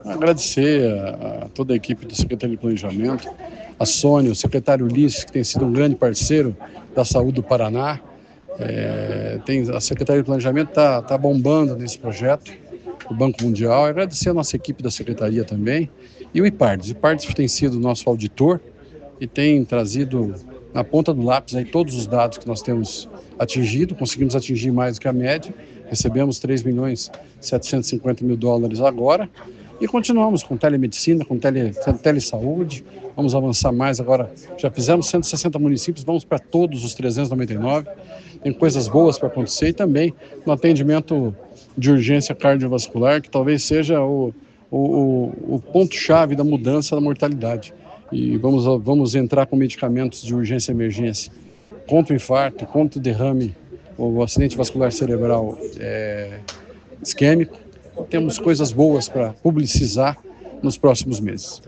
Sonora do secretário da Saúde, Beto Preto, sobre o repasse do Banco Mundial para a Secretaria da Saúde